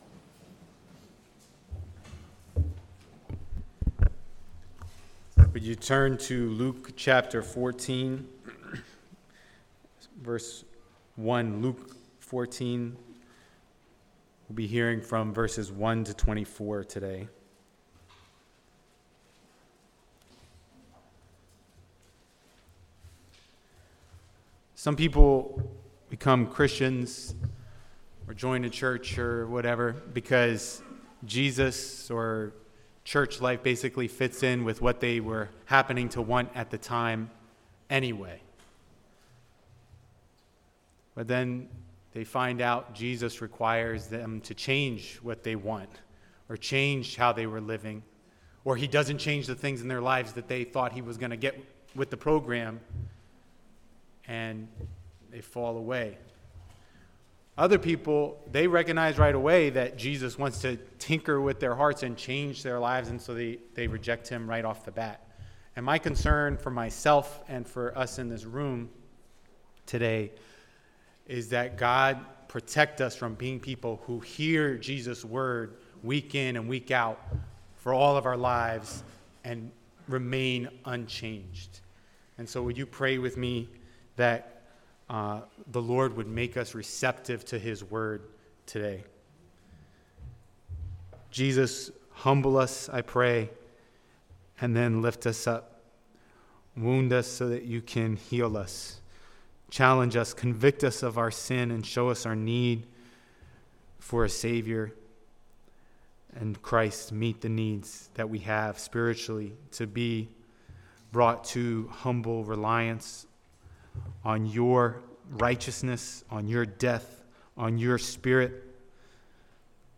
Passage: 1 Peter 5:1-5 Service Type: Sunday Morning